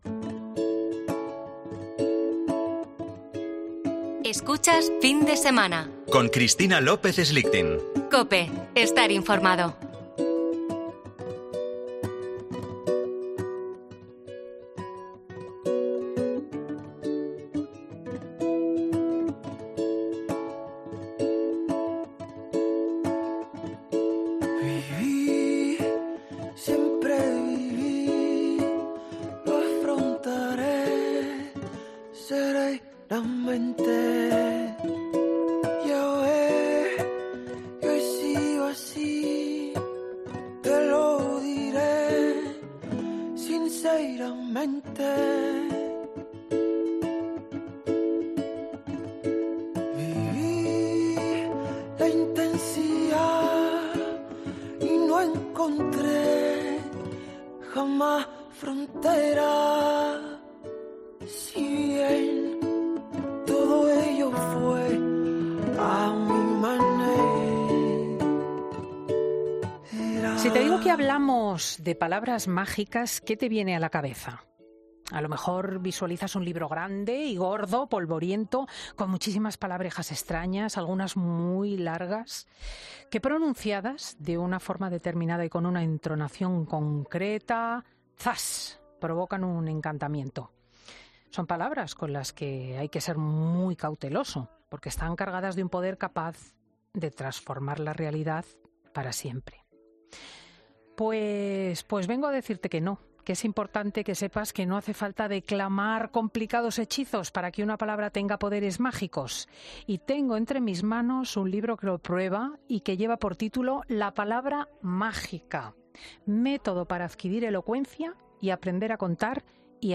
El actor ha pasado por el estudio de Fin de Semana con su libro 'La Palabra Mágica' bajo el brazo. ¿Cómo hablar en público y no morir en el intento?